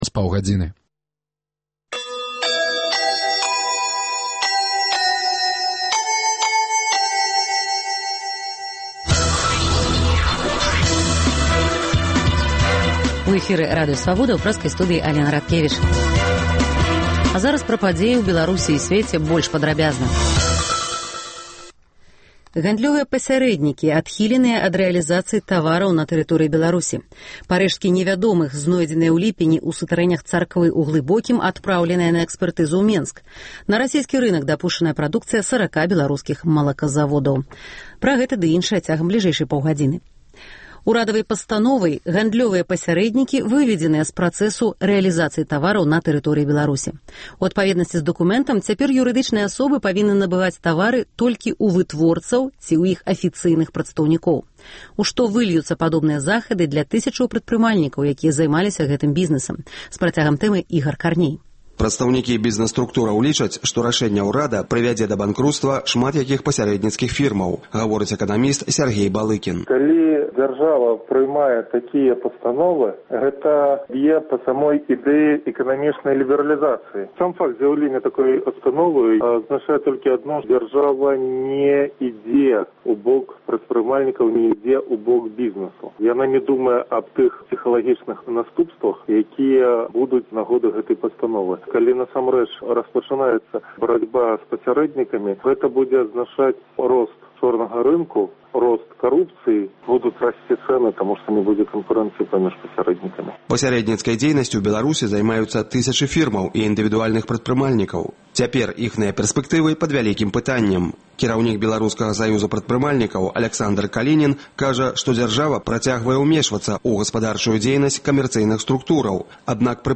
Паведамленьні нашых карэспандэнтаў, званкі слухачоў, апытаньні ў гарадах і мястэчках Беларусі